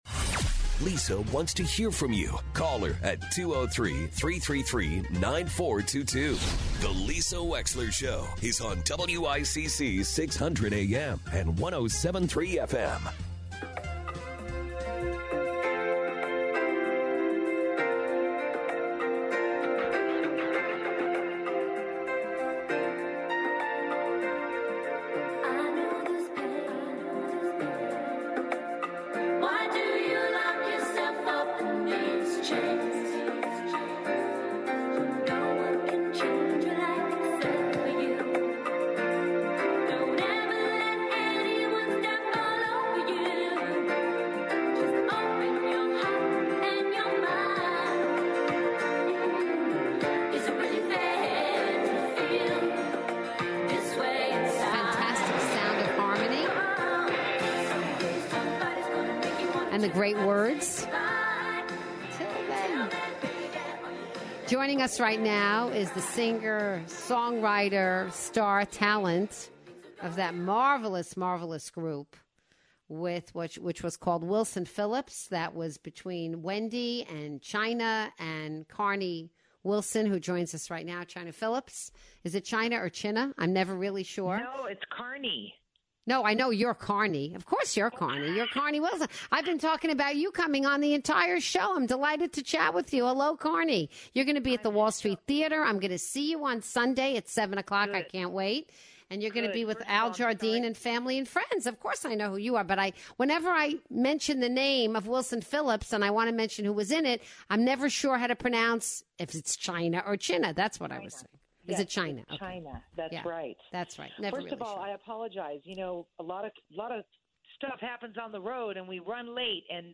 Carnie Wilson calls in to talk about her life in music and her upcoming show at the Wall Street Theatre in Norwalk this Sunday!